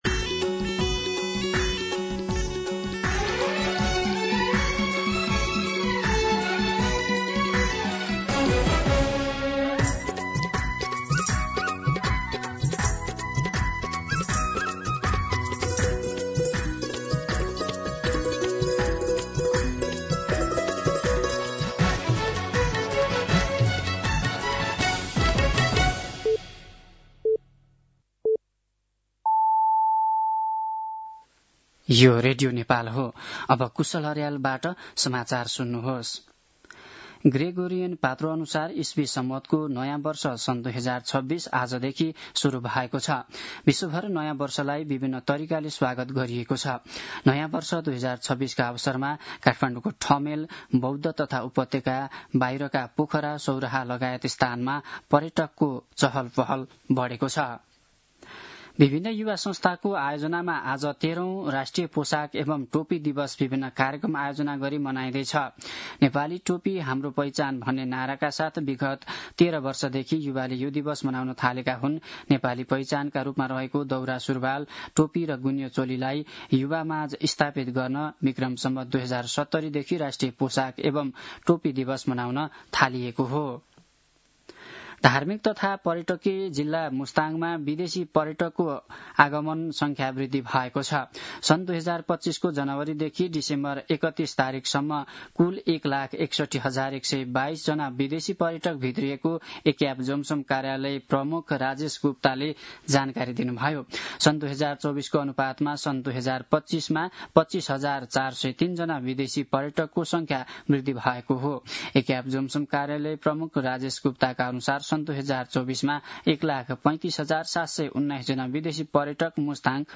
मध्यान्ह १२ बजेको नेपाली समाचार : १७ पुष , २०८२